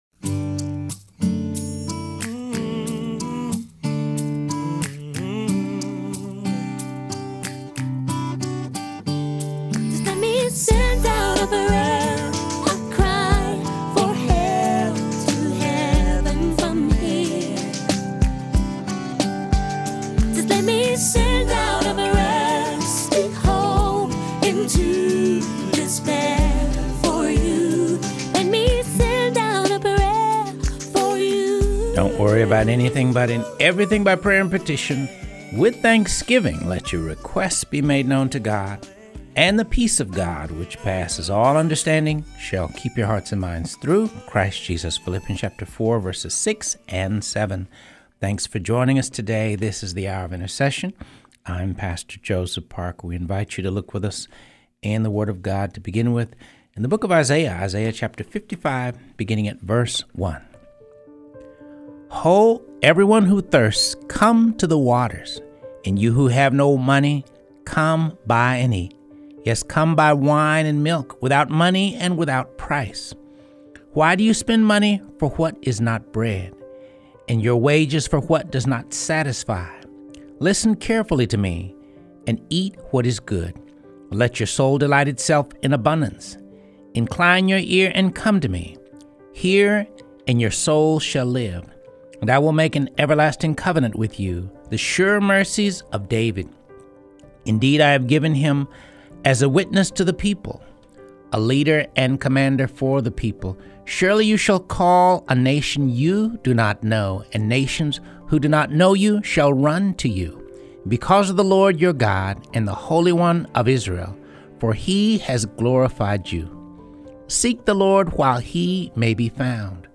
reads from this amazing book on this episode of Hour of Intercession. Show notes: 1 Chronicles 23:1-26:8 (NKJV), 2 Peter 1:1-3:18 (NKJV)